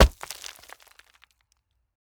RockHitingGround_5.wav